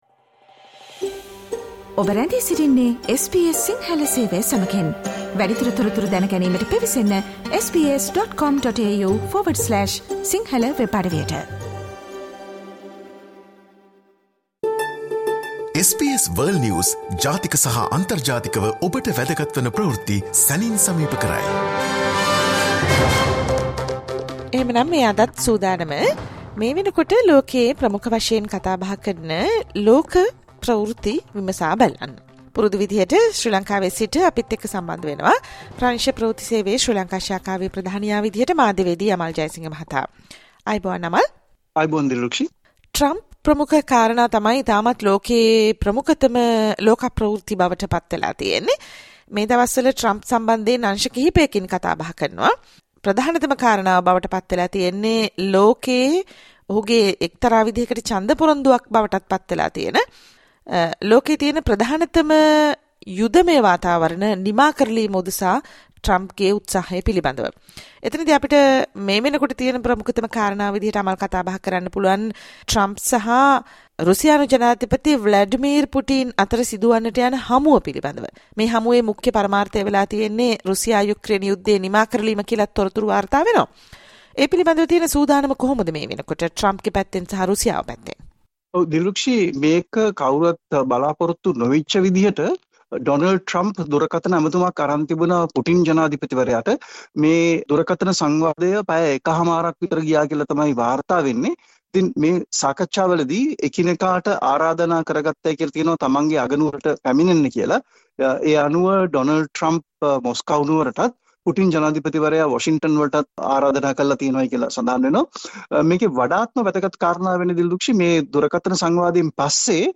Listen to the week's most important world news highlights